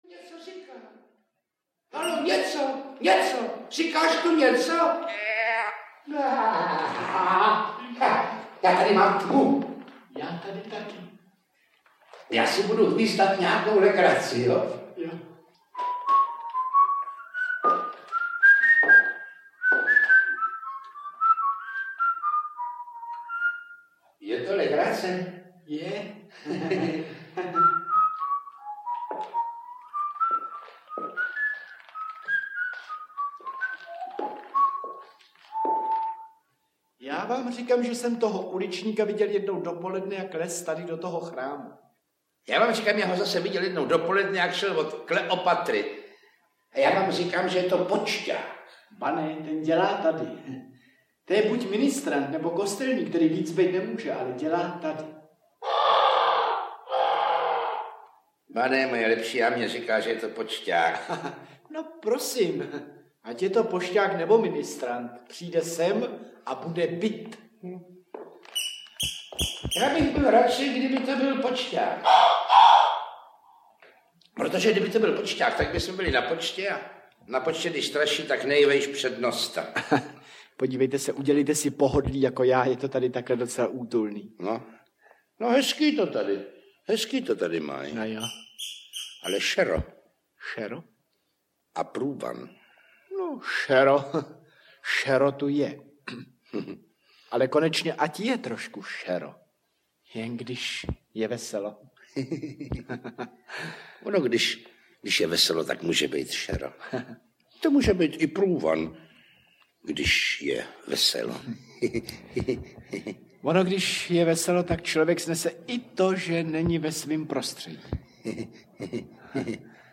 Dobře odtajněný Miroslav Horníček audiokniha
Ukázka z knihy
Soubor nahrávek dosud nikdy nevydaných, ale i známých či zapomenutých, v jedinečném, mnohahodinovém kompletu K letošnímu stému výročí narození velkého mistra slova vychází komplet známých, dříve vydaných, ale i zapomenutých, či dosud nikdy nevydaných nahrávek. Miroslav Horníček je zde (kromě několika kuriózních výjimek) představen jako herec, konferenciér, klaun a interpret především vlastních textů.
Přinášíme záznamy řady her divadla Semafor s Miroslavem Horníčkem v hlavní roli, neznámé verze již dříve vydaných pořadů a recitály dosud nevydané.